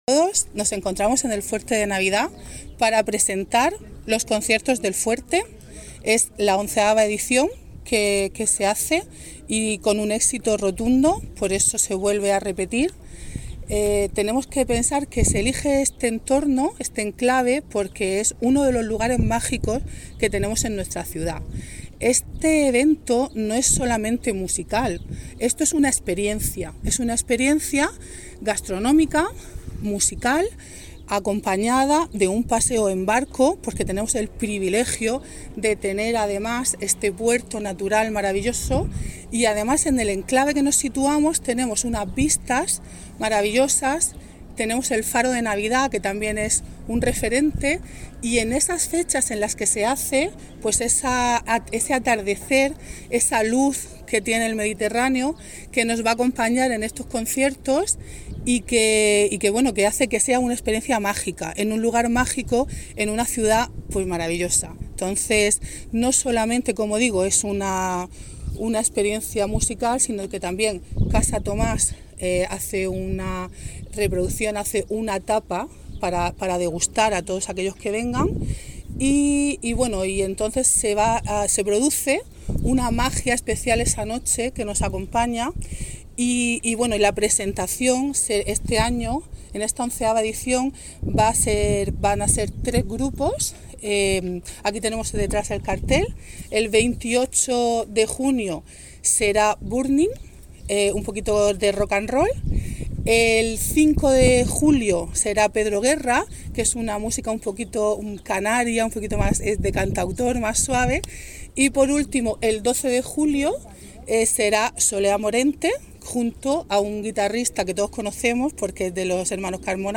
Enlace a Presentación de los conciertos del Fuerte de Navidad de Cartagena, con declaraciones de la concejal Beatriz Sánchez